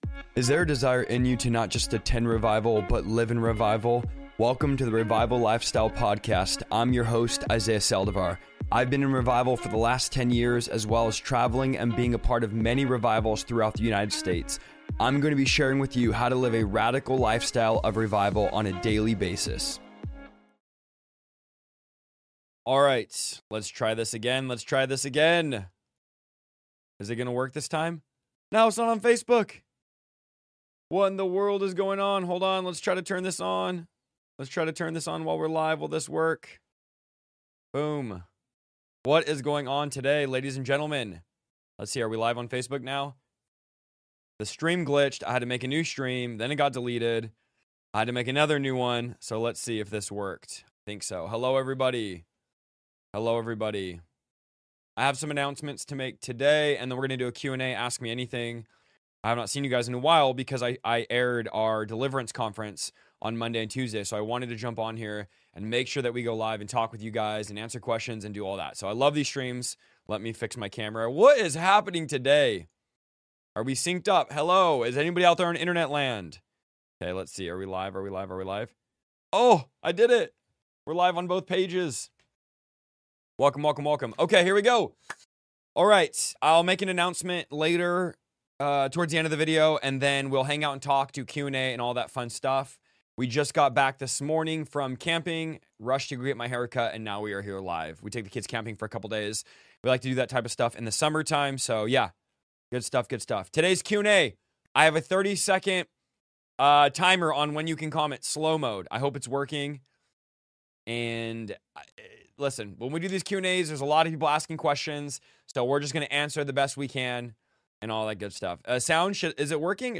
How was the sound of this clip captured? Ask Me Anything LIVE!